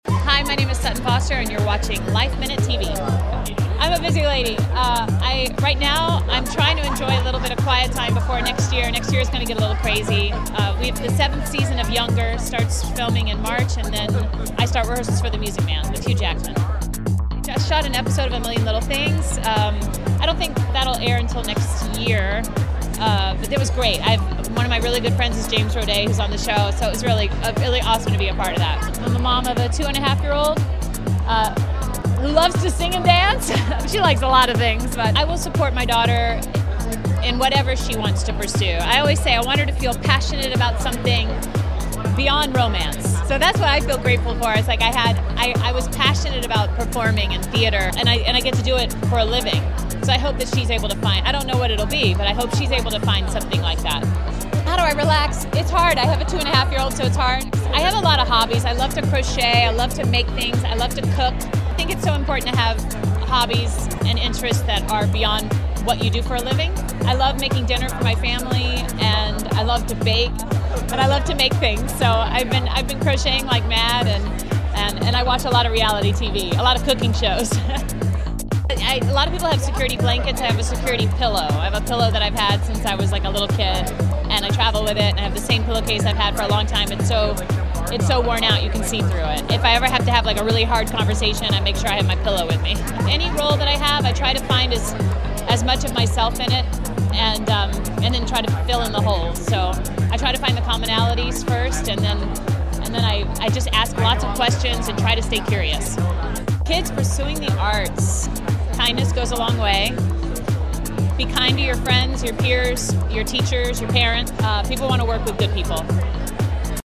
So how does the Mom relax when she’s not working? We found out when we spoke to her at the Arthur Miller Foundation Honors in New York City last month.